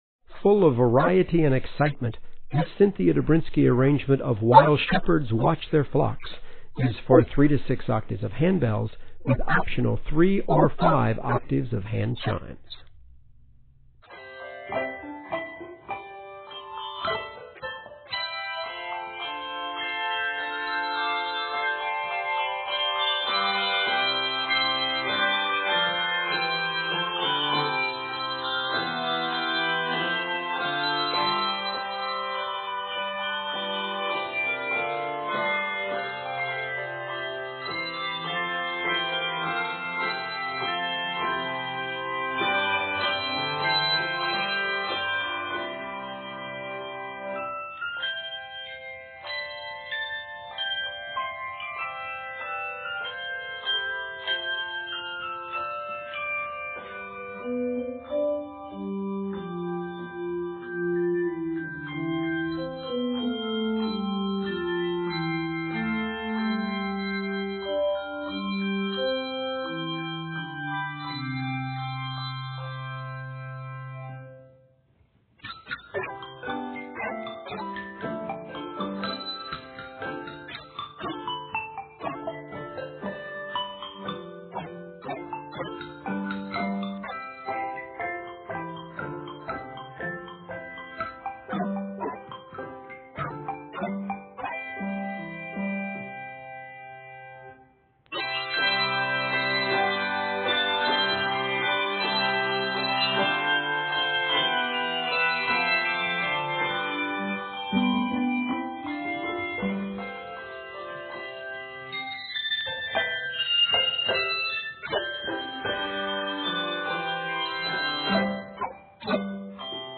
Utilizing the variation form